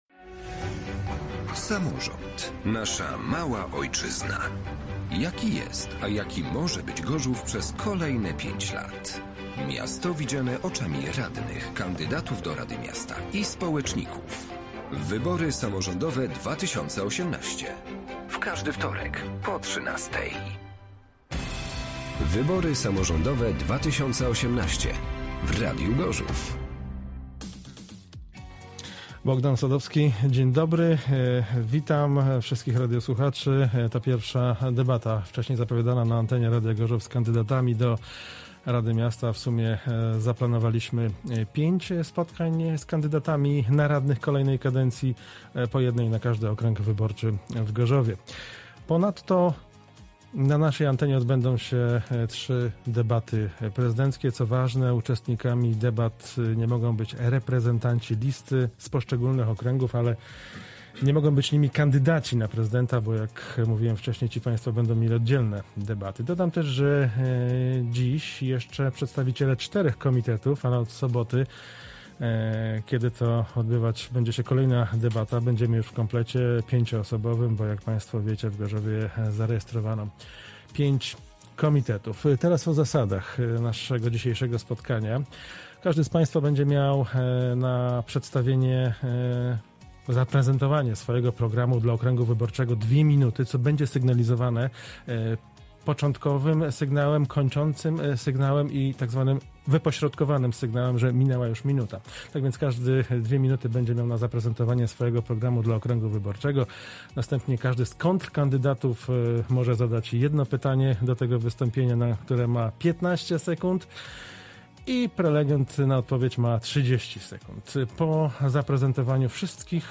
Samorząd nasza Mała Ojczyzna DEBATA kandydatów na radnych z okręgu wyborczego nr 1